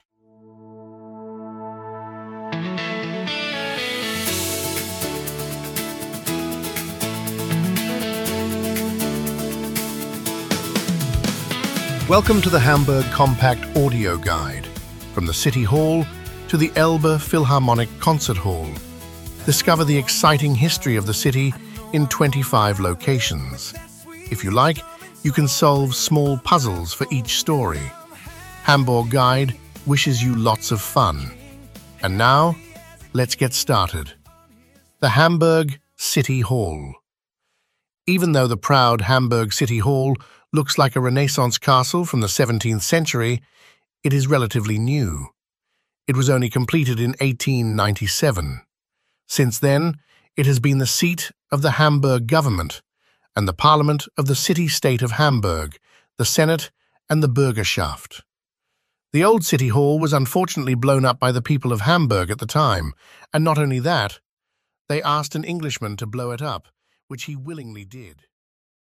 Explore Hamburg on a self-guided walking tour with English audio narration.
Self-guided audio tour through Hamburg's city center to the Elbphilharmonie
Preview-Hamburg-City-Hall-Hamborg-Guide-Audio-Guide.mp3